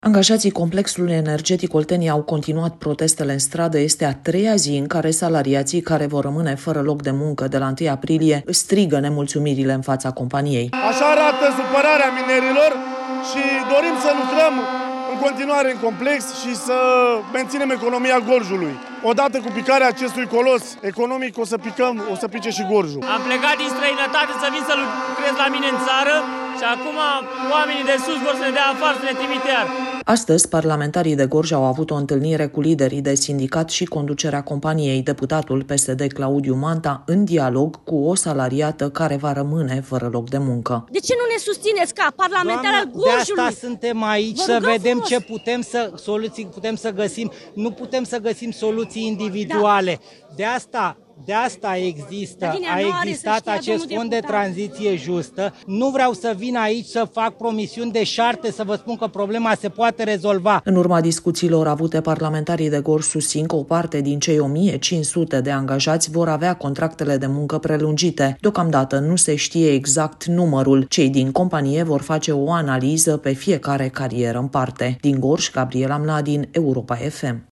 Este a treia zi în care salariații care vor rămâne fără loc de muncă de la 1 aprilie își strigă nemulțumirea în fața companiei.
Deputatul PSD Claudiu Manta, în dialog cu o salariată care va rămâne fără loc de muncă: